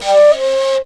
FLUTELIN08.wav